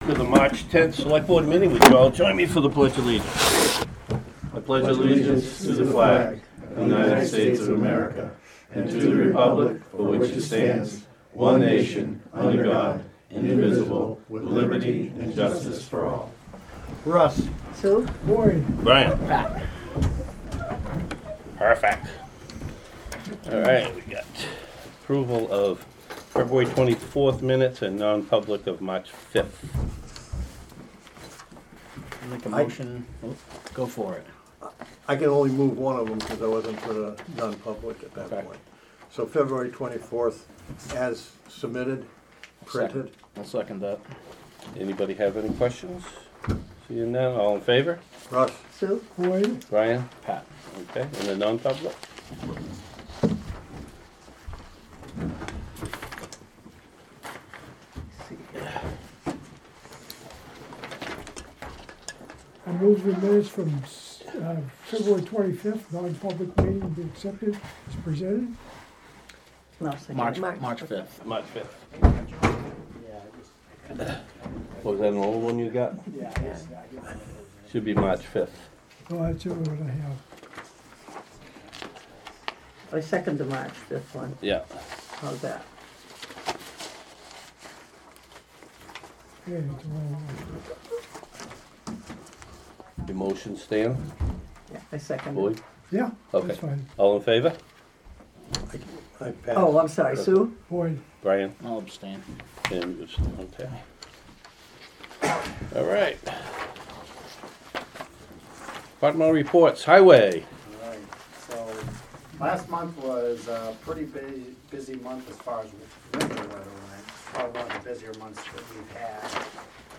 Audio recordings of committee and board meetings.